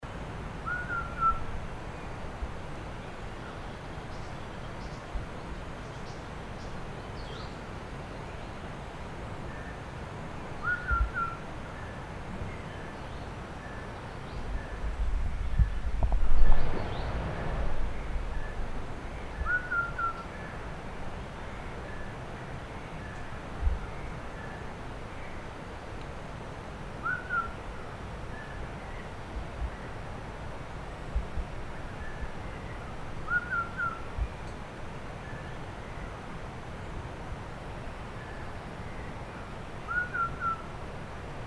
Pomatorhin à col roux ( Pomatorhinus ruficollis ) ssp stridulus
Chant enregistré le 06 mai 2012, en Chine, province du Fujian, réserve de Dai Yun Shan.